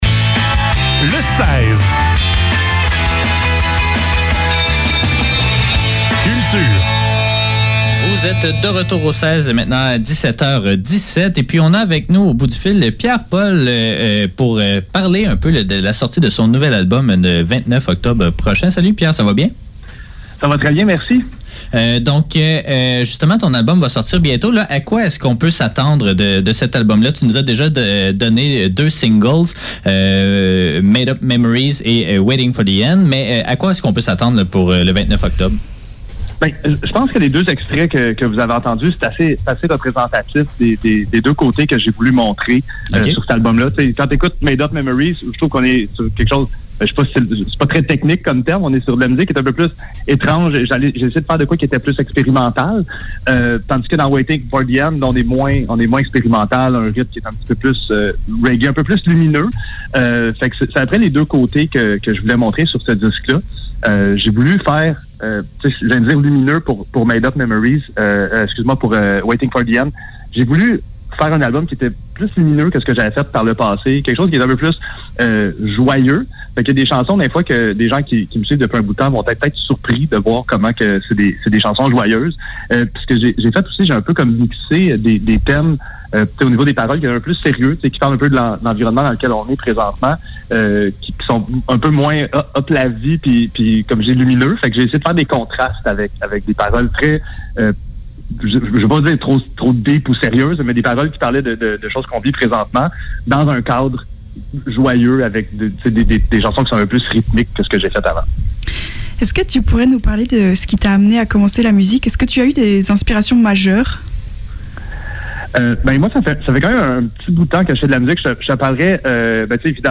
Le seize - Entrevue